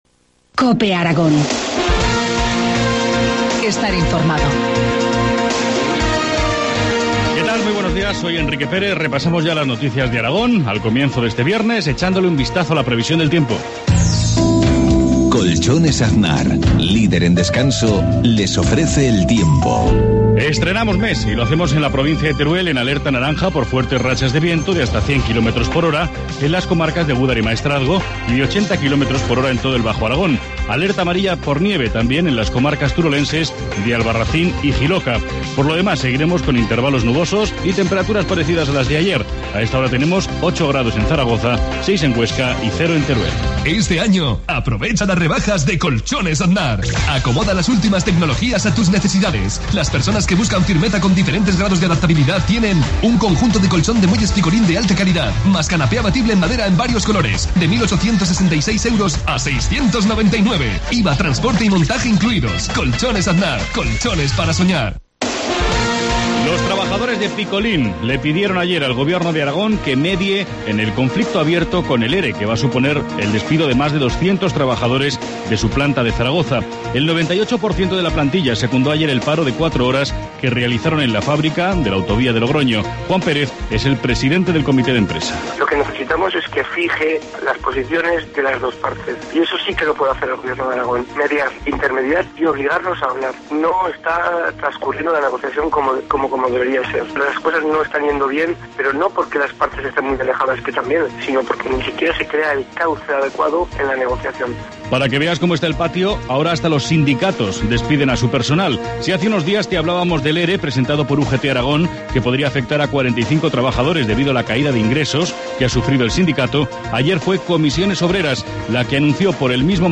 Informativo matinal, viernes 1 de febrero, 7.53 horas